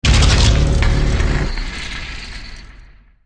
playerdies_8.ogg